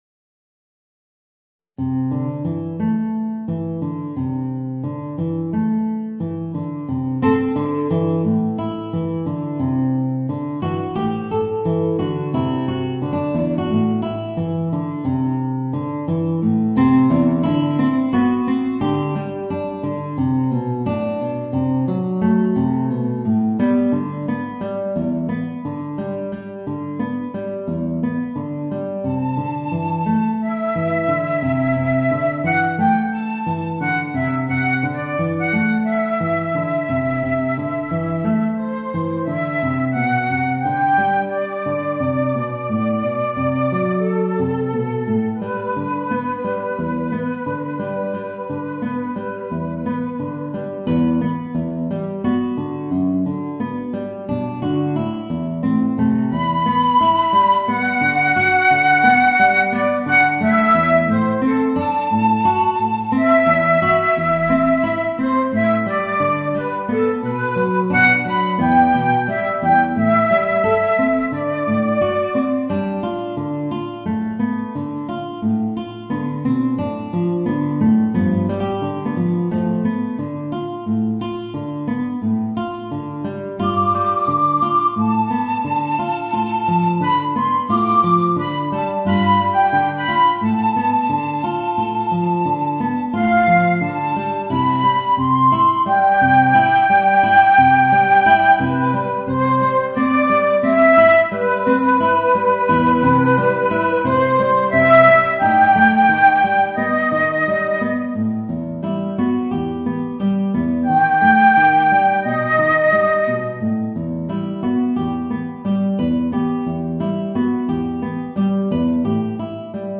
Voicing: Flute and Guitar